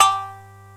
Index of /90_sSampleCDs/AKAI S-Series CD-ROM Sound Library VOL-1/3056SHAMISEN